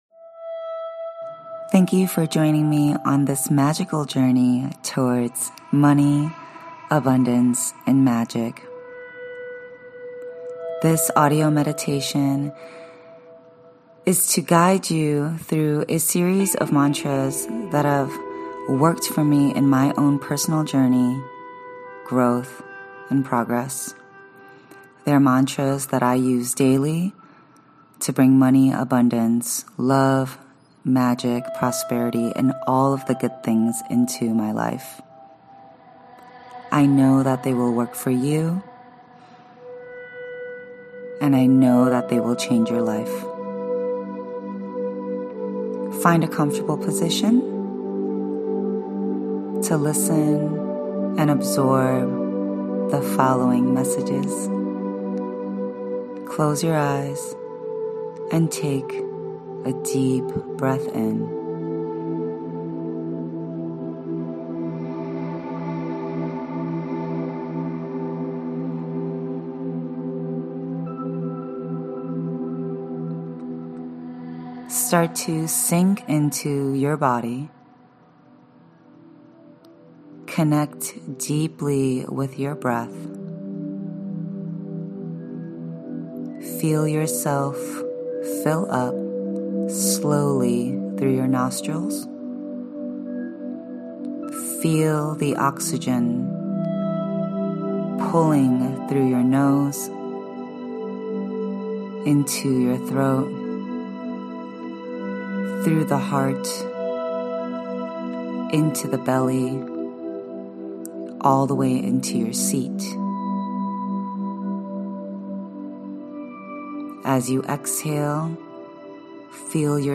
I created this audio meditation to put all my life-changing mantras into one place. These are some of the more impactful mantras I have used throughout my personal and professional growth.